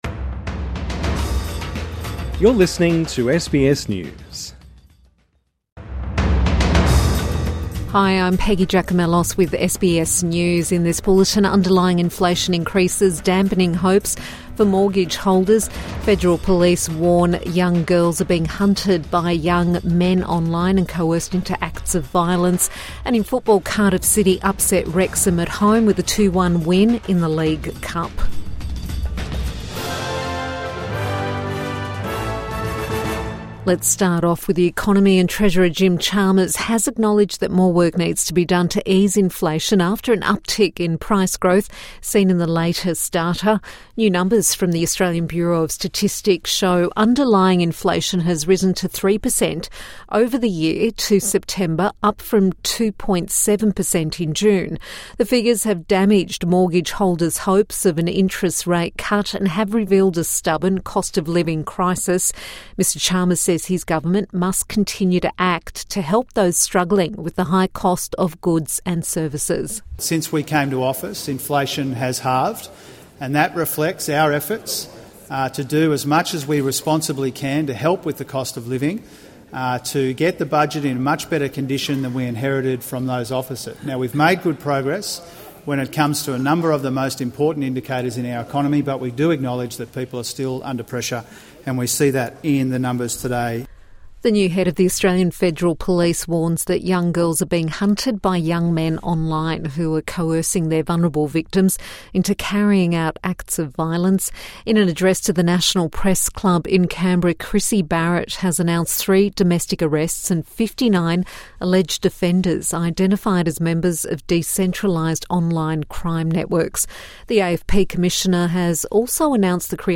Evening News Bulletin 29 October 2025